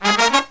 Index of /90_sSampleCDs/USB Soundscan vol.29 - Killer Brass Riffs [AKAI] 1CD/Partition B/09-108SL SB5